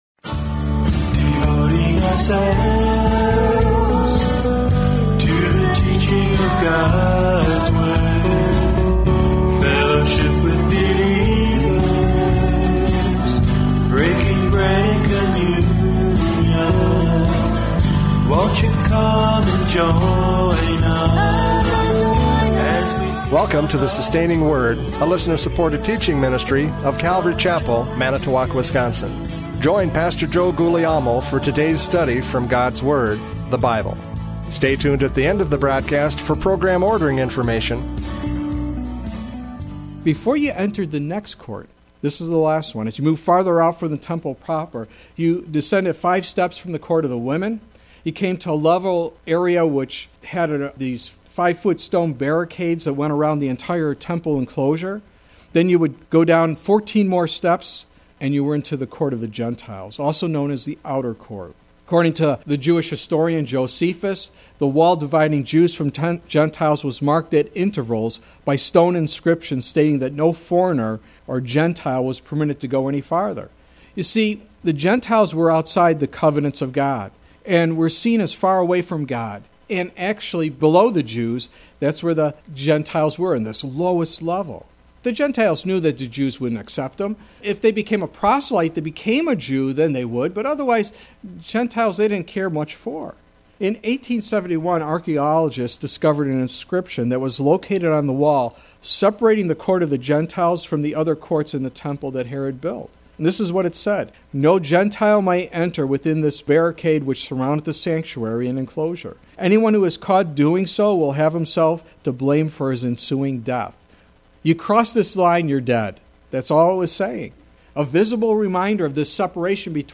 John 2:13-17 Service Type: Radio Programs « John 2:13-17 Sinner to Saint!